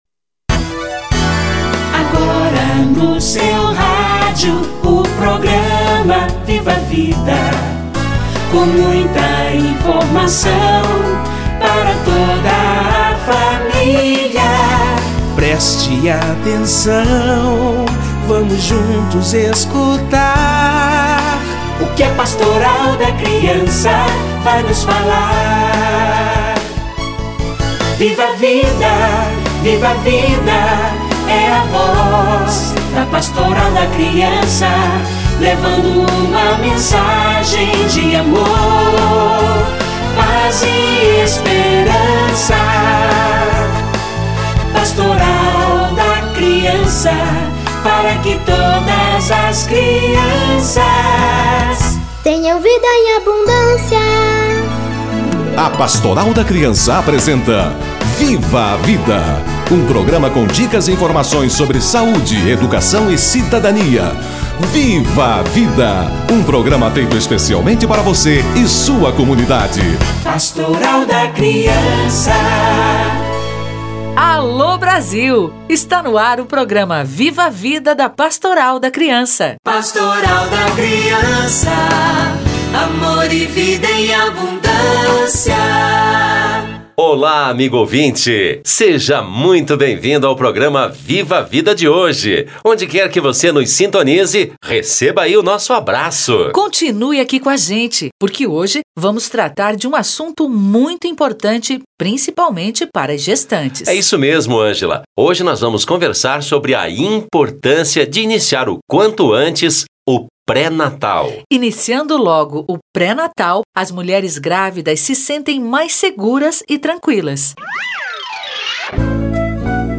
Mutirão em busca das gestante - Entrevista